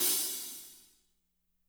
-18  CHH H-L.wav